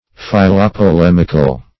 Philopolemical \Phil`o*po*lem"ic*al\, a.